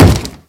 sounds / mob / zombie / wood4.ogg
wood4.ogg